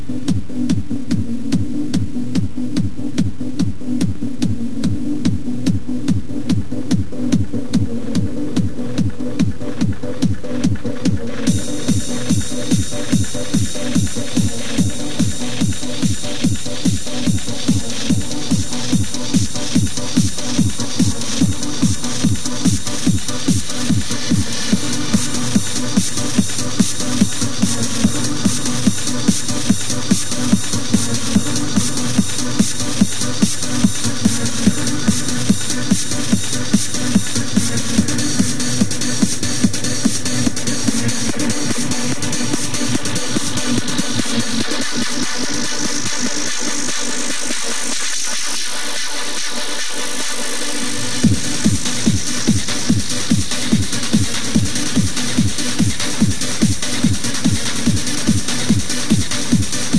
techno2.wav